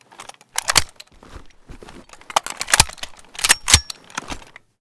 vz58_reload_empty.ogg